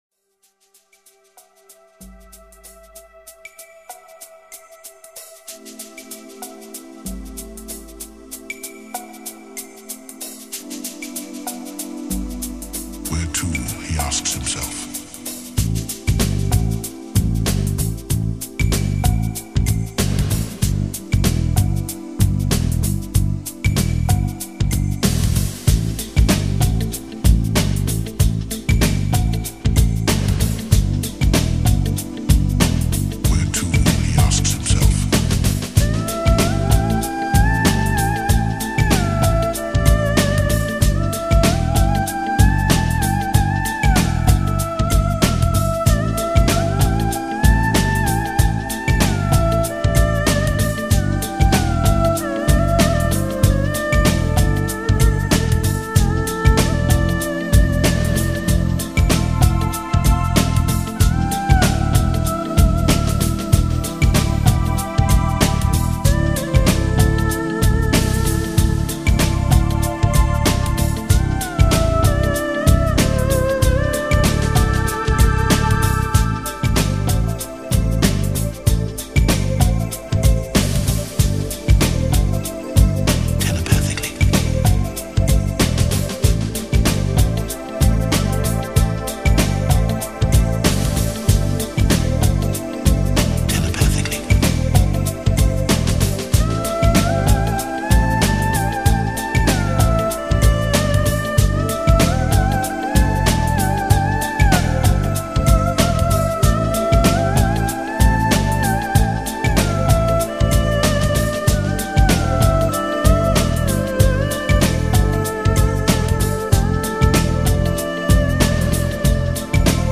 神秘主义的主题用现代电子乐来表现，
女声亮丽动人，如夜莺一般悦耳；男声低沉隐约，令人着迷。